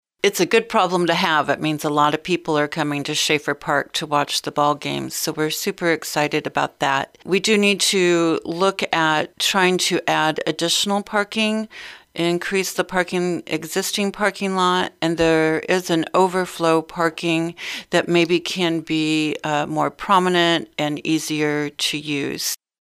City Administrator Roze Frampton says it is a problem they see at some bigger games.